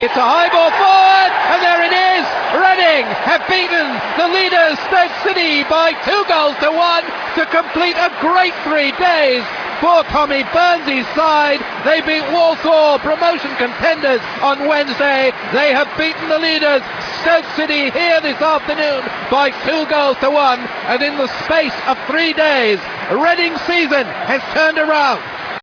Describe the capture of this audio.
Match Audio